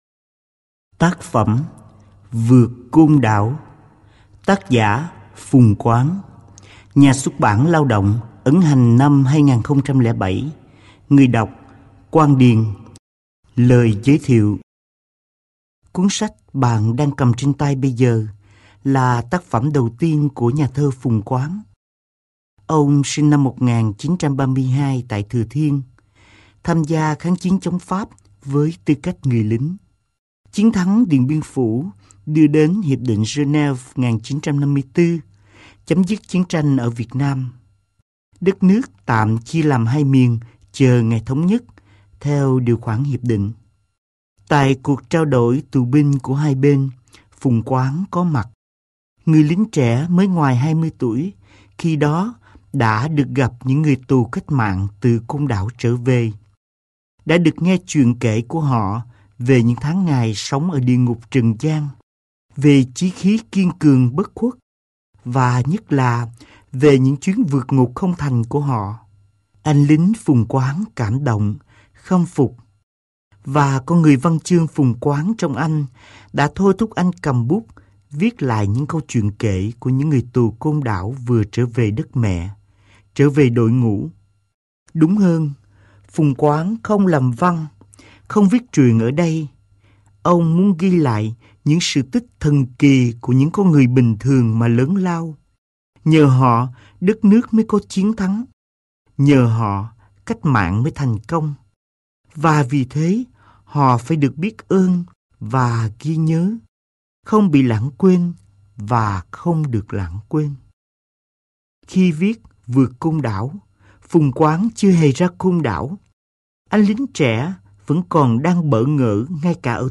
Sách nói | Vượt côn đảo 1